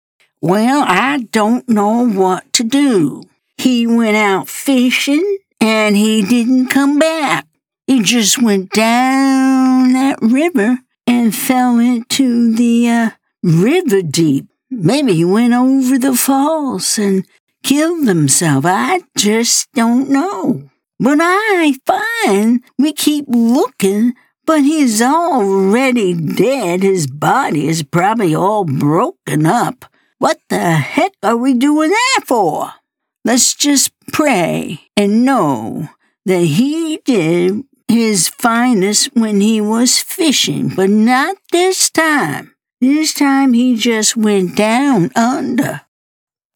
Narrations are recorded with a home studio-quality MSB Mic, providing consistent and reliable performance.
Southern Belle